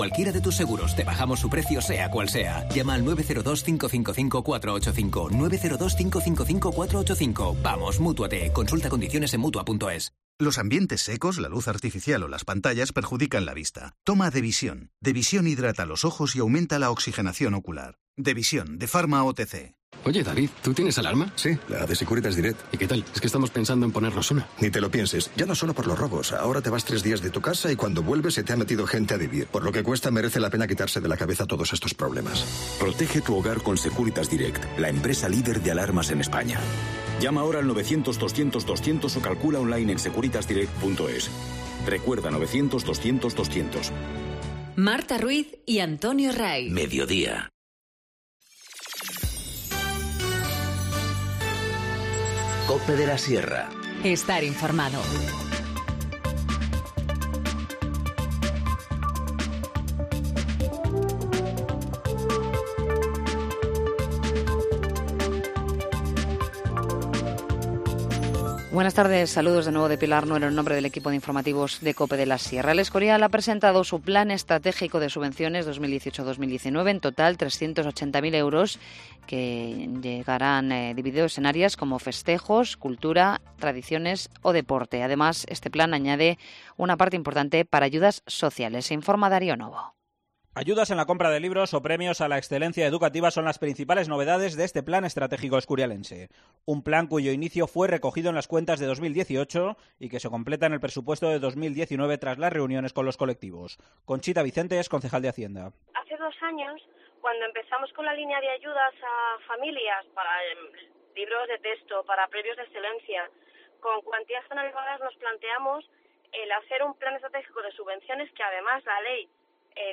Informativo Mediodía 13 marzo 14:50h